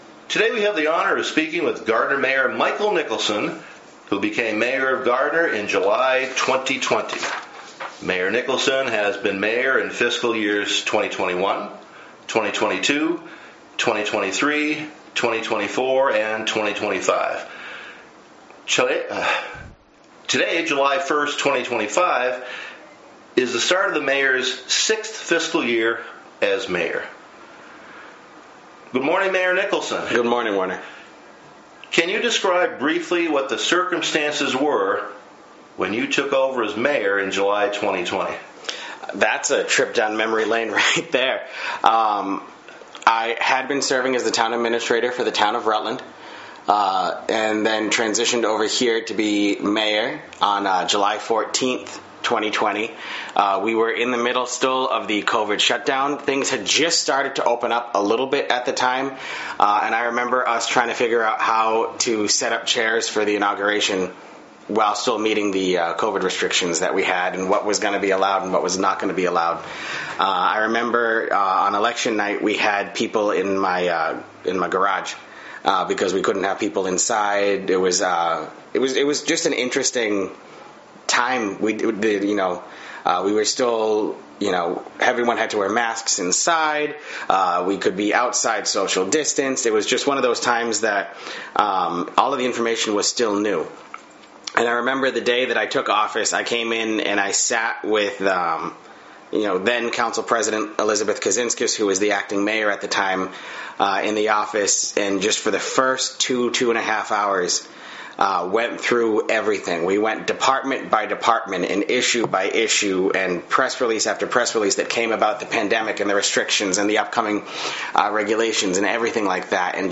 Listen to the July 1, 2025 interview with Mayor Michael Nicholson on any device.
Fiscal-6-Interview-Michael-Nicholson-7-1-25-FINAL.mp3